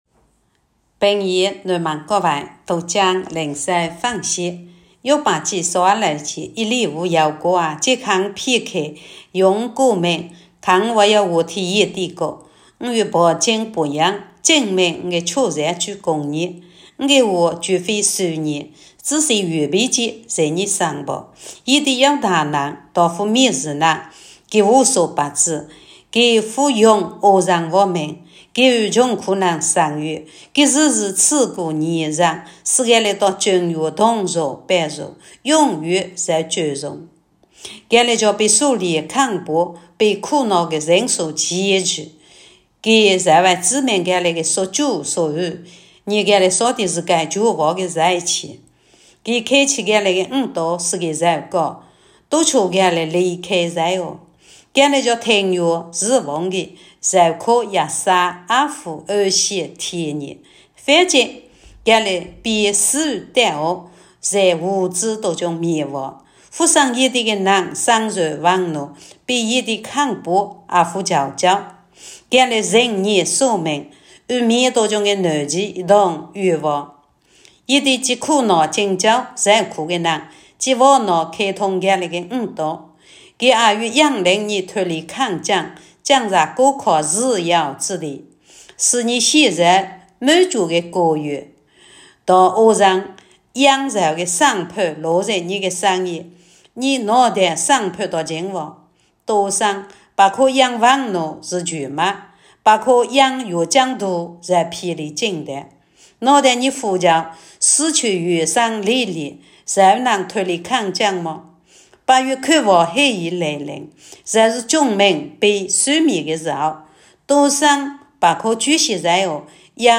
平阳话朗读——伯36